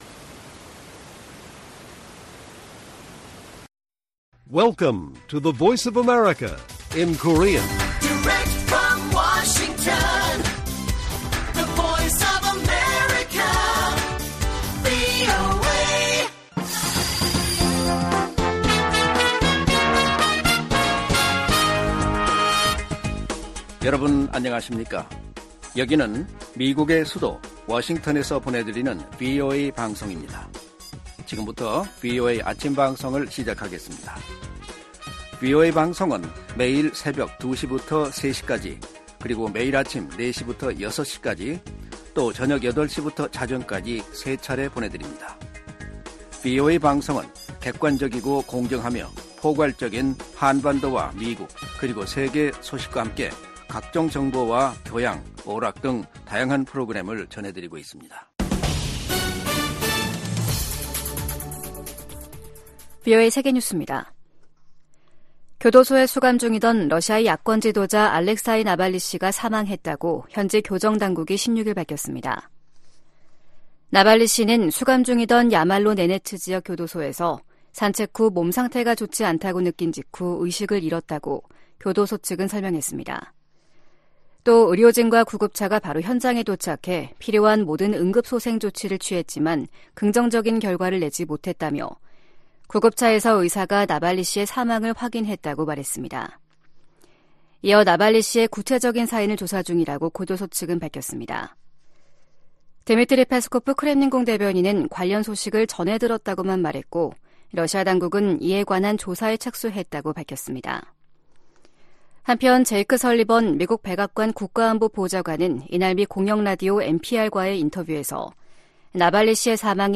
세계 뉴스와 함께 미국의 모든 것을 소개하는 '생방송 여기는 워싱턴입니다', 2024년 2월 17일 아침 방송입니다. '지구촌 오늘'에서는 이스라엘-하마스 전쟁과 우크라이나 문제가 핵심 의제가 될 뮌헨안보회의 개막 소식 전해드리고, '아메리카 나우'에서는 도널드 트럼프 전 대통령의 '성추문 입박음' 의혹 관련 형사 사건 첫 재판 일정이 다음 달 25일로 확정된 이야기 살펴보겠습니다.